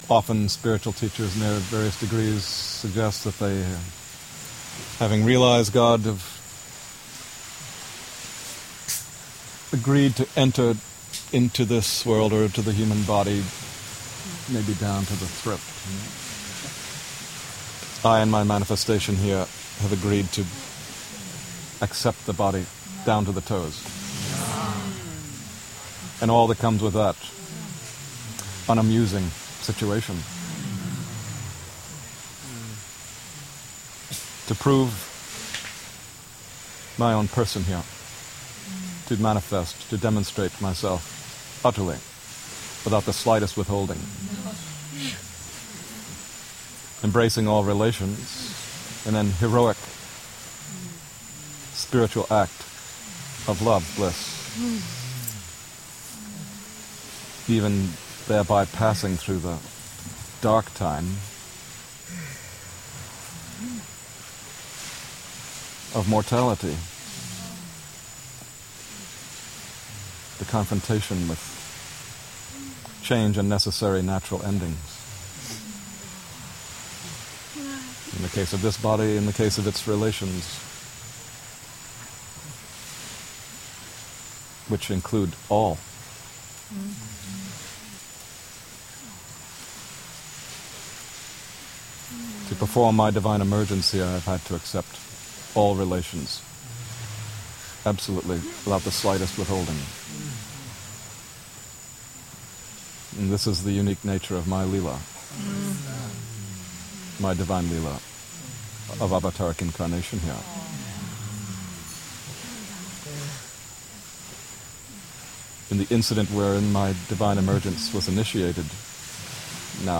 Excerpt from Discourse